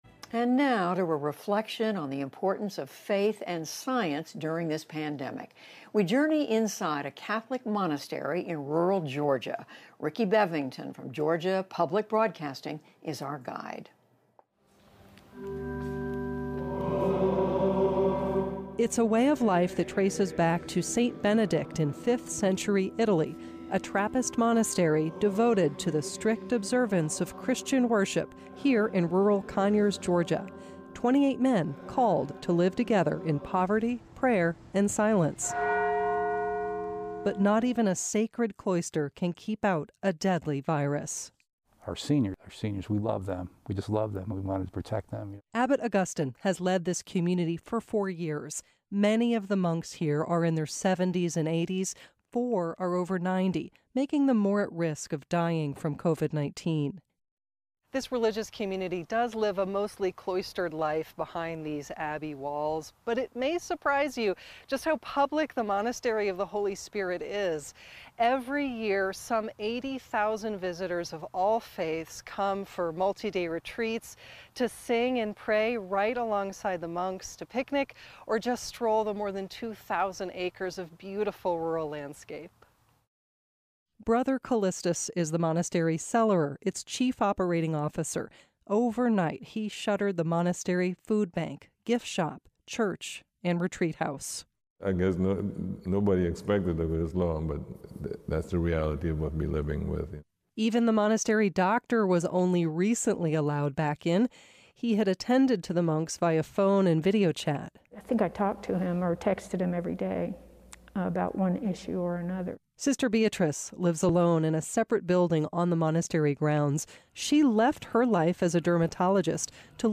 英语访谈节目:新冠期间信仰的重要性